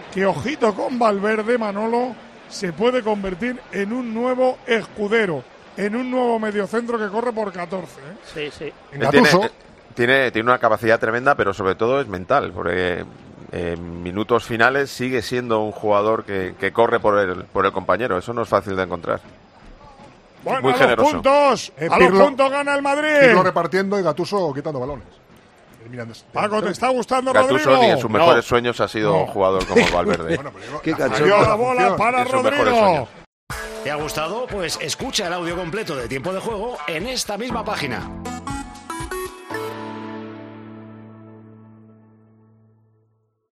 Durante la retransmisión de Tiempo de Juego, una jugada de Valverde atrajo la atención a los comentaristas.